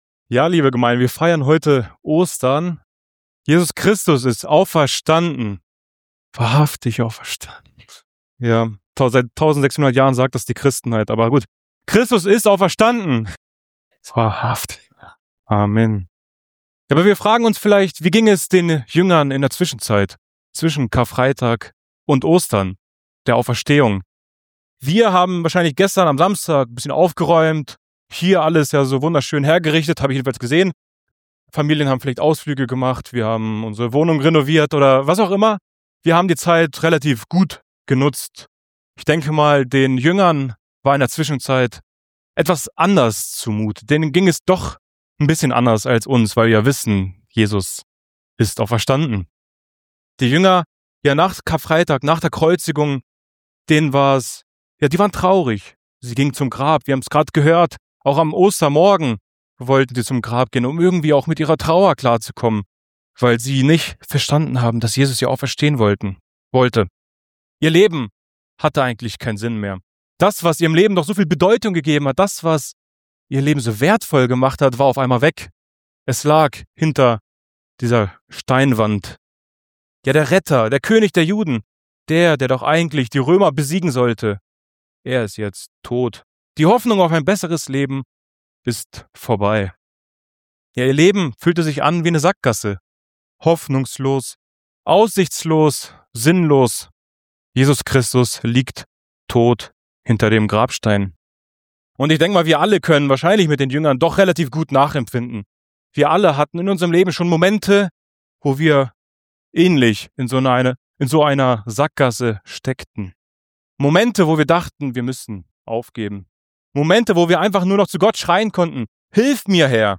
Predigtreihe Exodus - Part 5